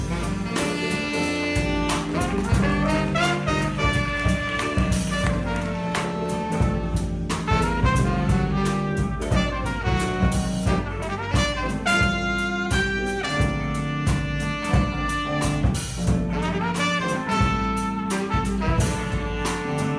Jimple Slosh is a hip-hop piece that I wrote for my jazz combo.
In this recording, I am playing Tenor Sax.